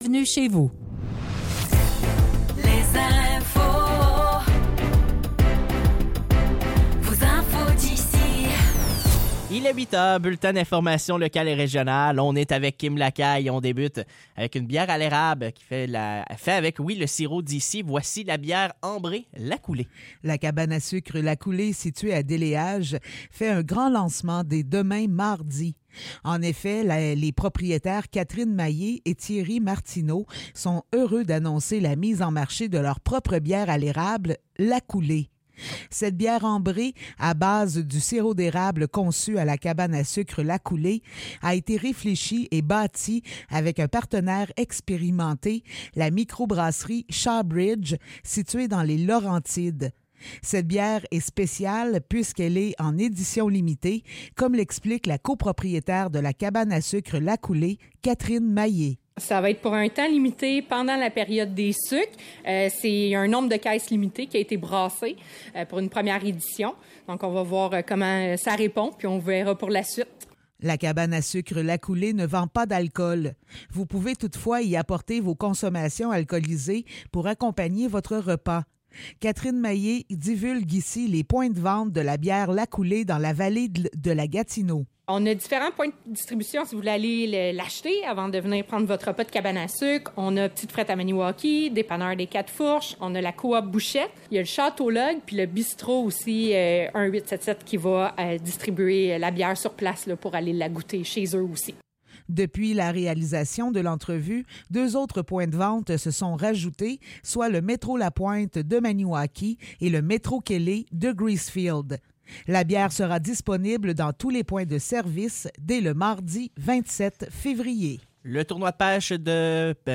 Nouvelles locales - 26 février 2024 - 8 h